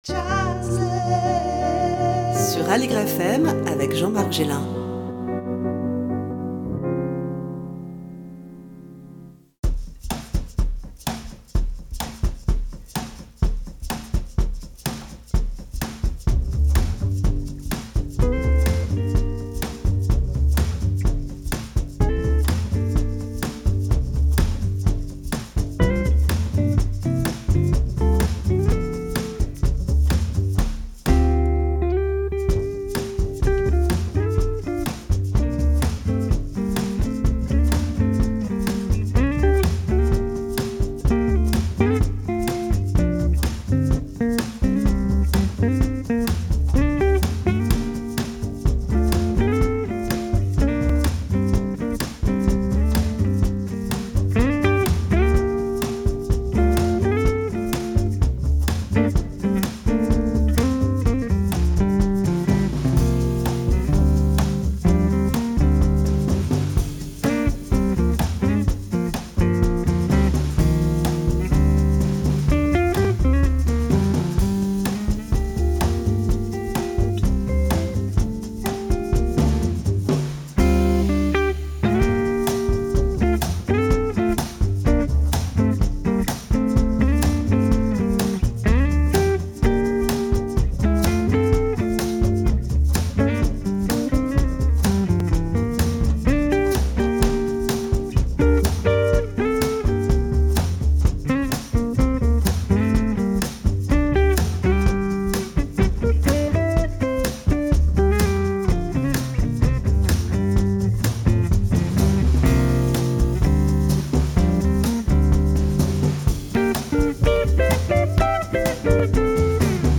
Avec le guitariste
la harpiste